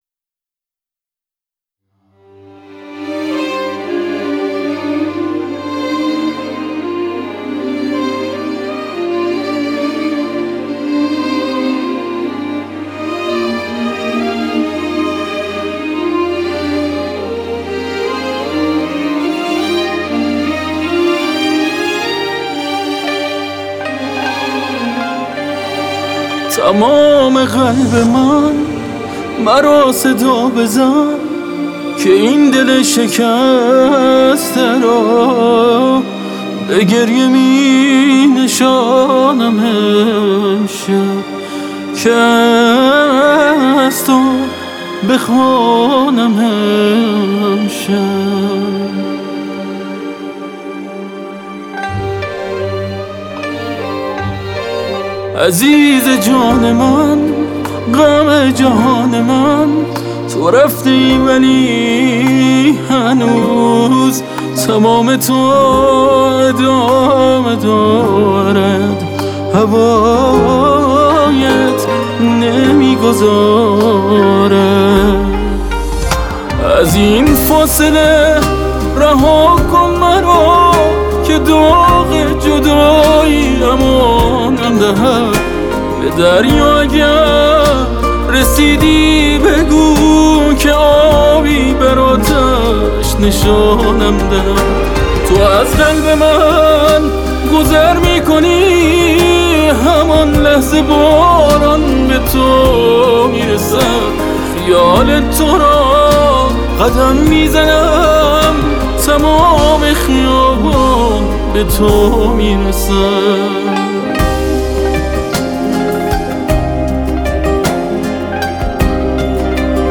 با تنظیمی لطیف و حضور پررنگ ویولن